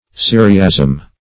Meaning of syriasm. syriasm synonyms, pronunciation, spelling and more from Free Dictionary.
Search Result for " syriasm" : The Collaborative International Dictionary of English v.0.48: Syriasm \Syr"i*asm\, n. A Syrian idiom; a Syrianism; a Syriacism.